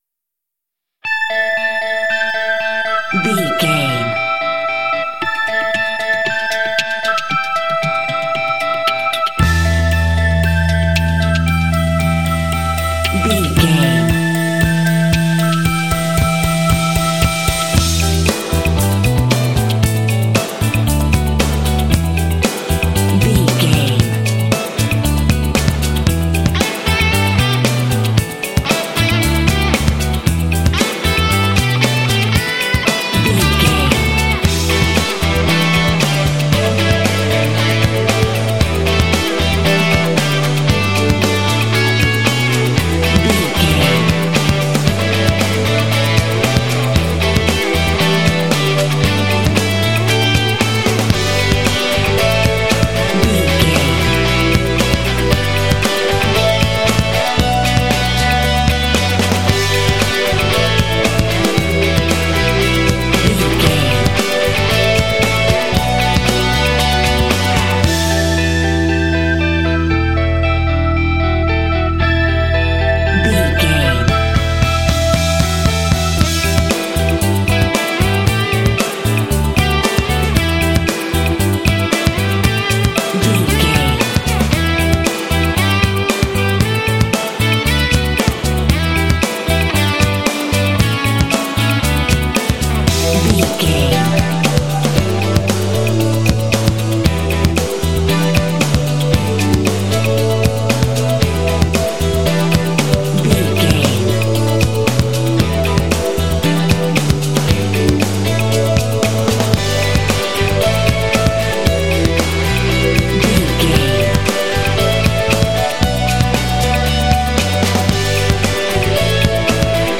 Dorian
cool
happy
groovy
bright
electric guitar
strings
bass guitar
synthesiser
alternative rock
symphonic rock